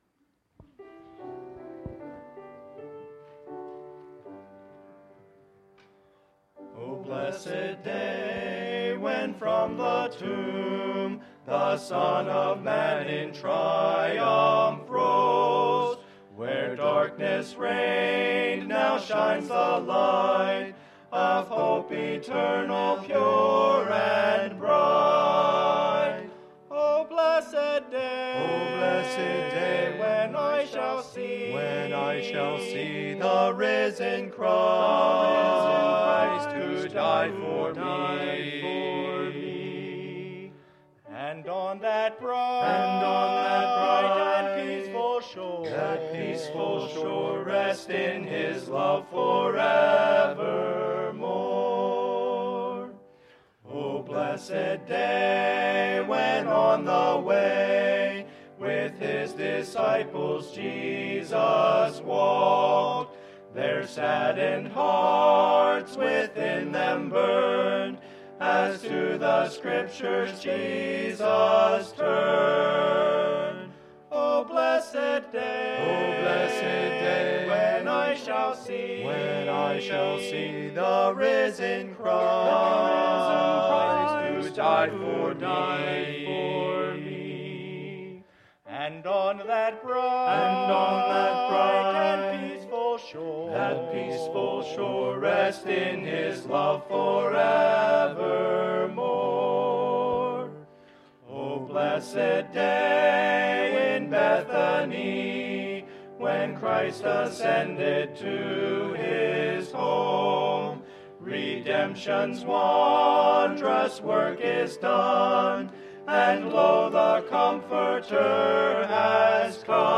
Sunday, March 27, 2016 – Sunday Morning Service